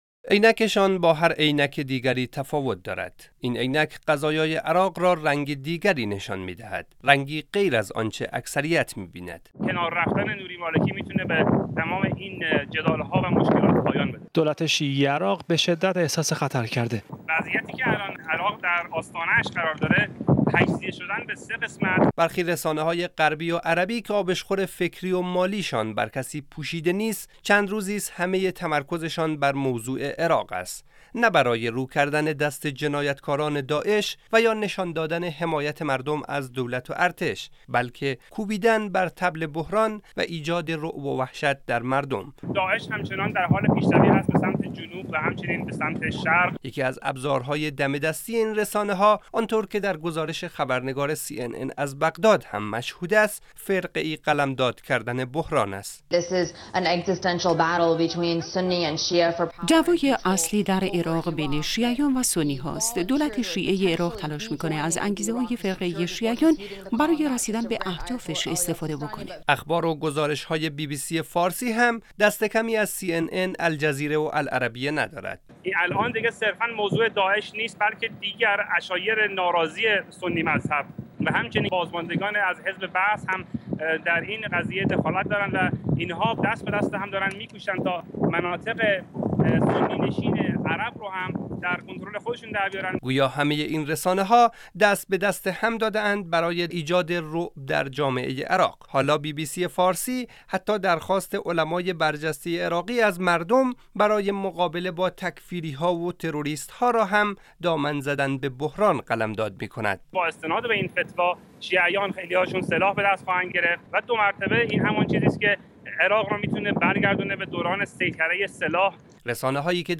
"گزارش شنیدنی" از رسانه های خارجی و اوضاع عراق - تسنیم